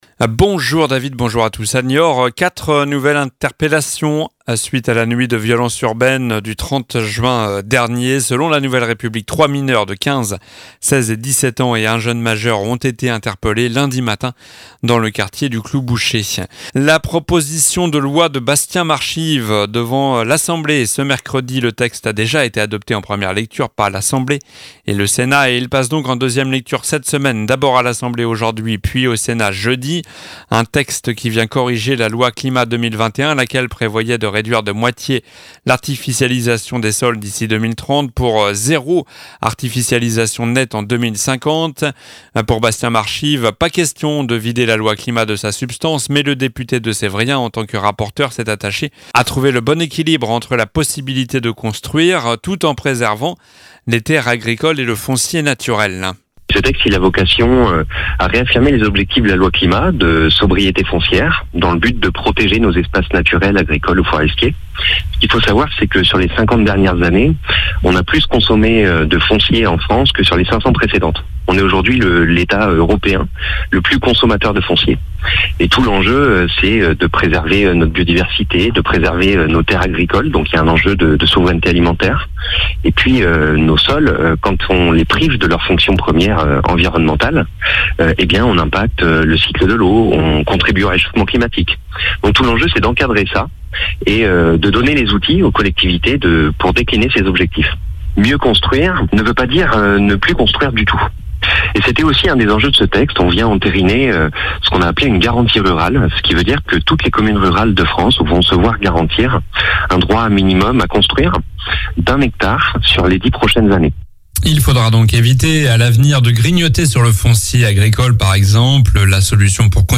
Journal du mercredi 12 juillet (midi)